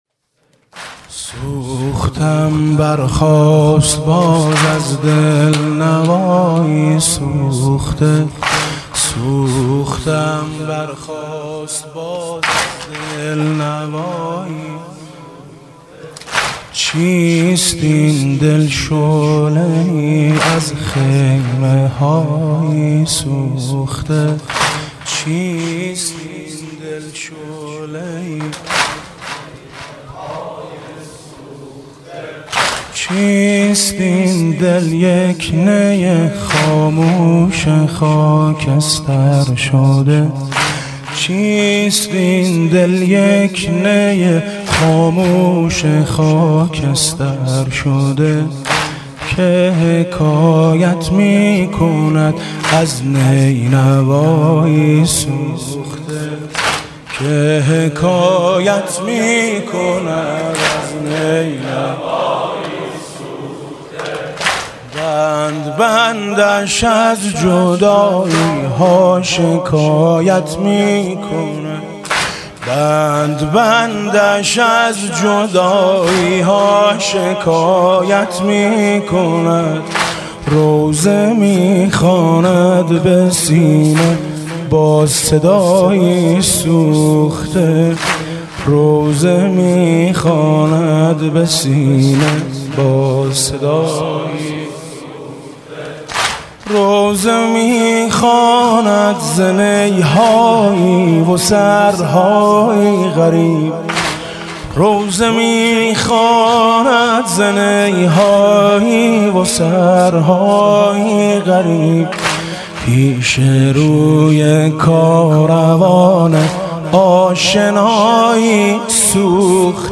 «محرم 1396» (شب پانزدهم) واحد: سوختم برخاست باز از دل نوایی سوخته